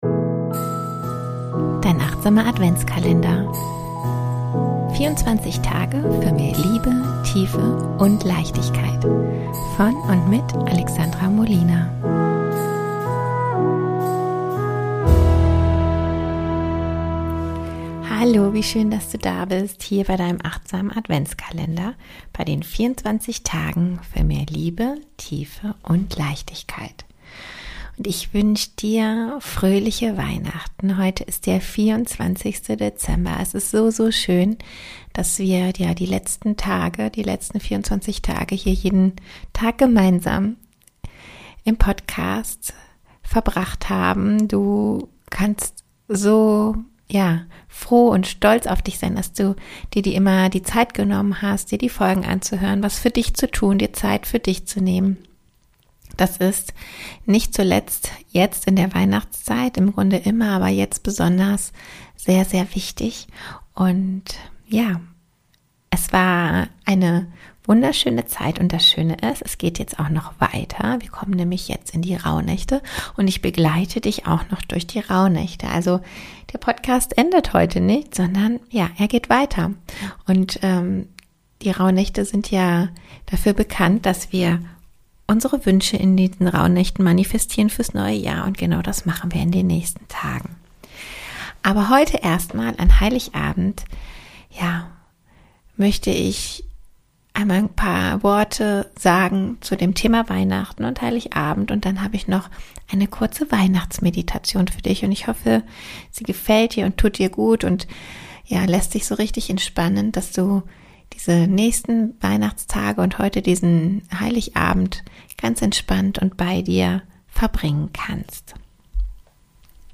Dein achtsamer Adventskalender - Tag 24 - Weihnachtsmeditation